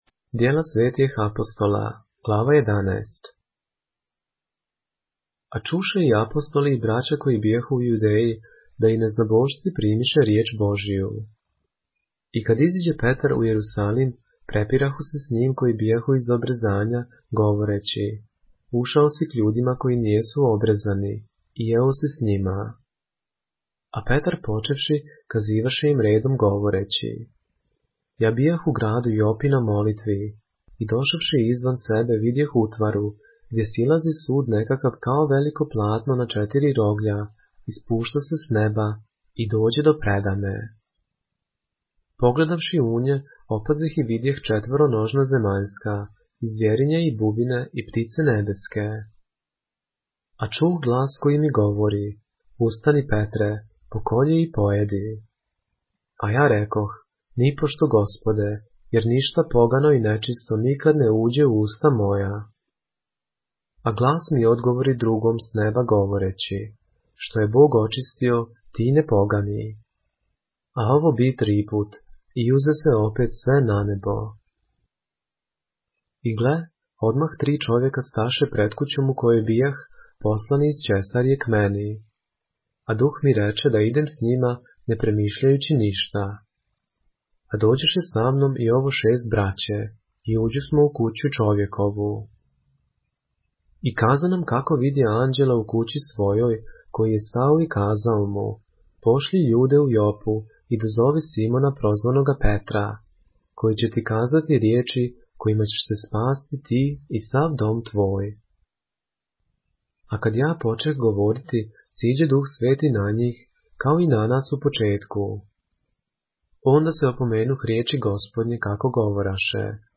поглавље српске Библије - са аудио нарације - Acts, chapter 11 of the Holy Bible in the Serbian language